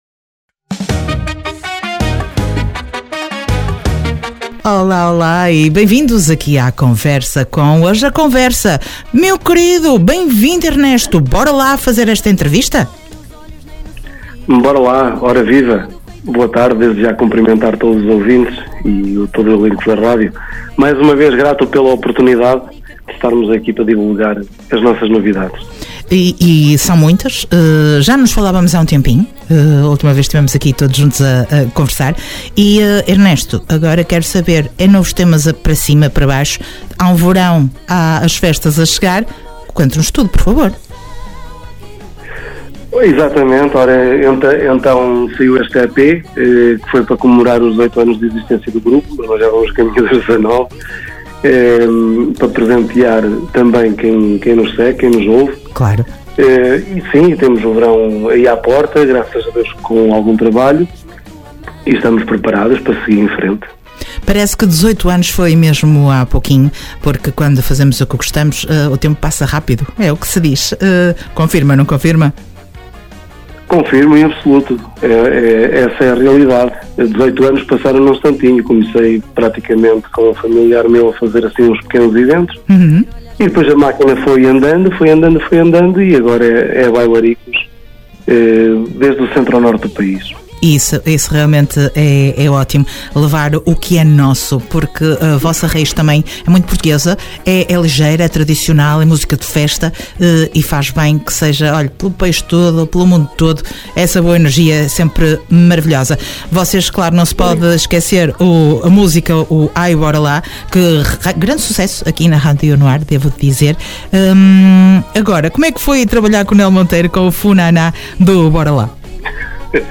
Entrevista ao Grupo Musical Bora Lá dia 16 de Abril.